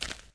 1 channel
OsoStep.wav